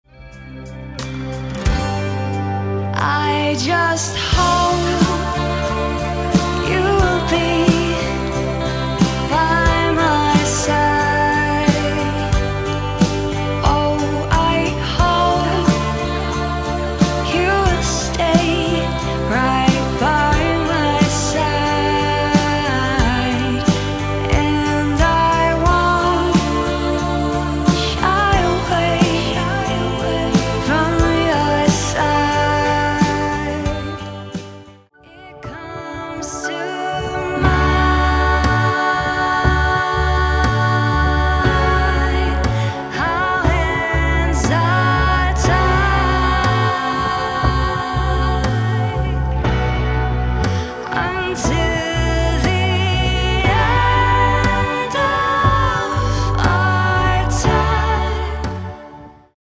Band (akustisk)
Intim akustisk koncert